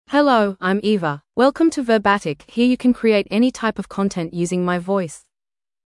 FemaleEnglish (Australia)
Eva is a female AI voice for English (Australia).
Voice sample
Listen to Eva's female English voice.
Female